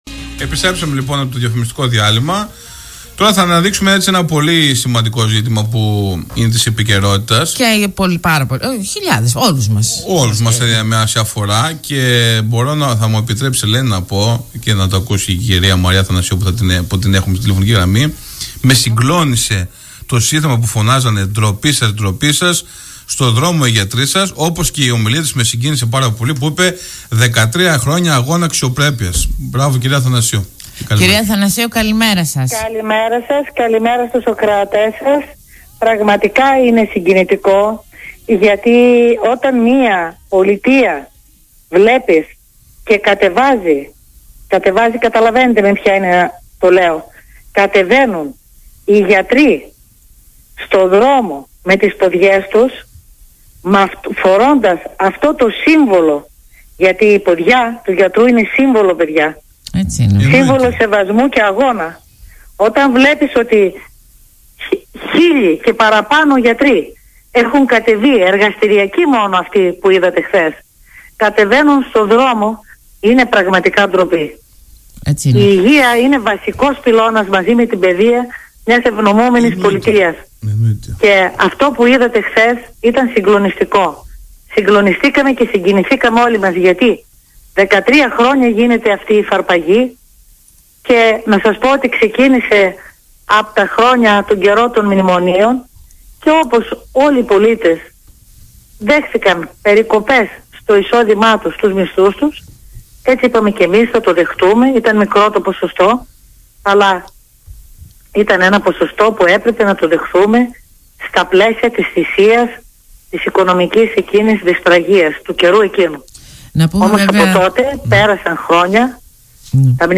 Απόσπασμα εκπομπής συνέντευξης